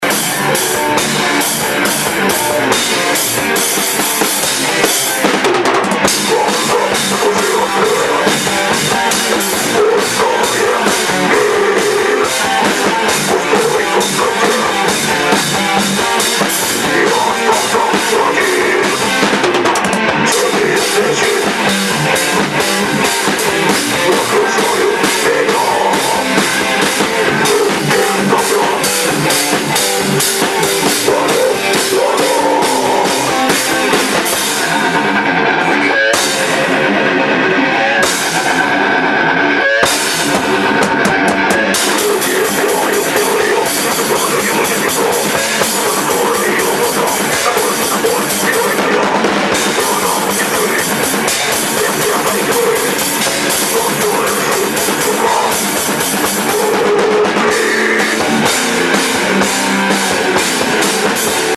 Дэт-метал композа нашей команды